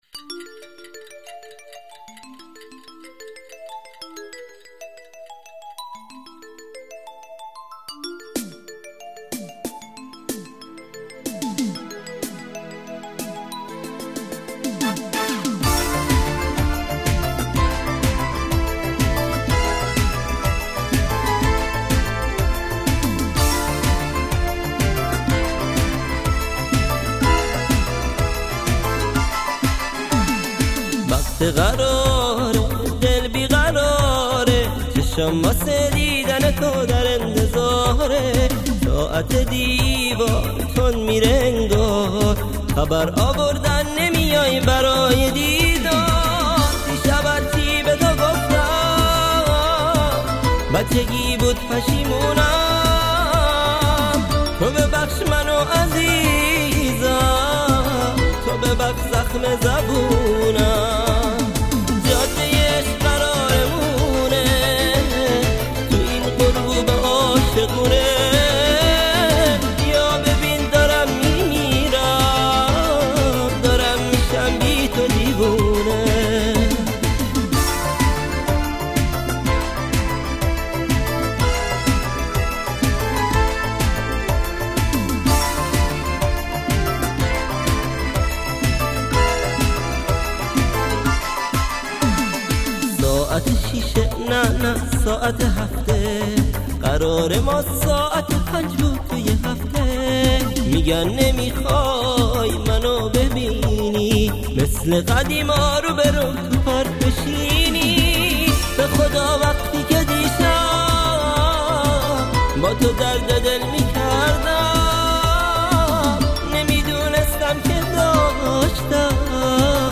ژانر: پاپ
توضیحات: ریمیکس شاد ترانه های قدیمی و خاطره انگیز